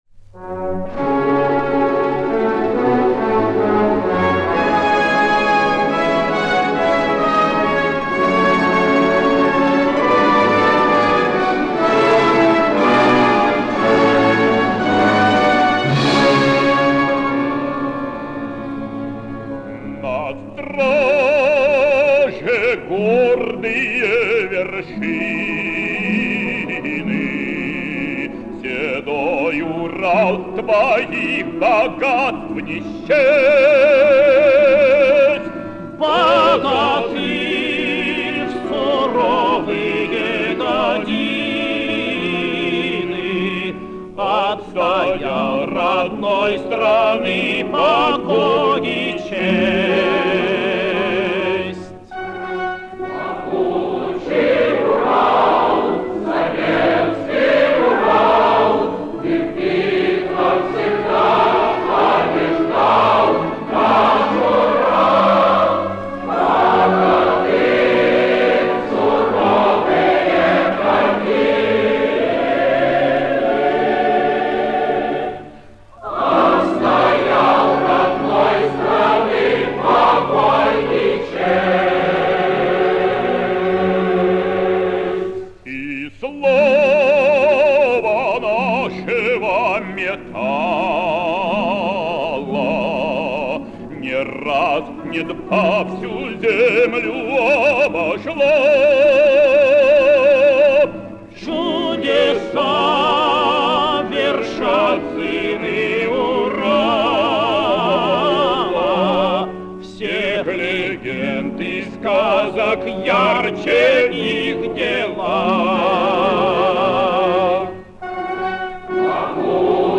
Вторая песня из документального к/ф.